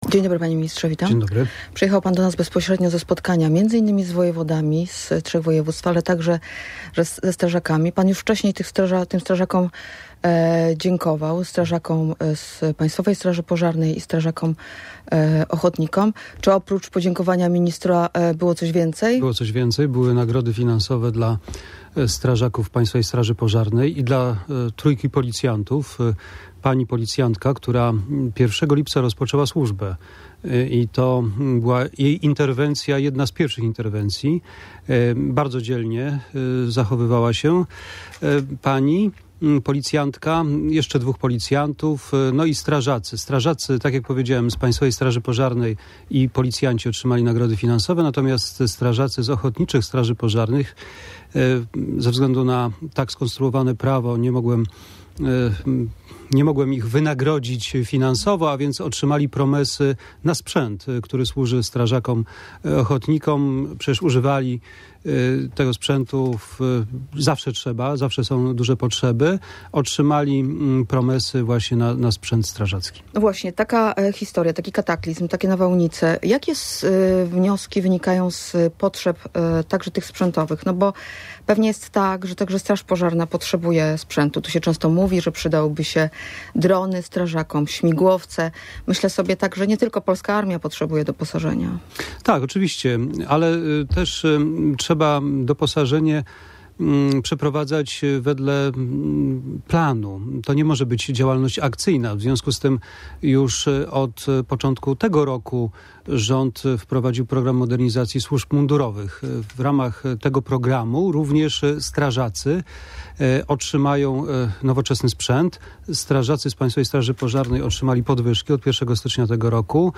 Będzie nowoczesny sprzęt dla strażaków. Dzięki temu będą mogli skuteczniej pomagać podczas kataklizmów – tak mówił w Radiu Gdańsk Mariusz Błaszczak.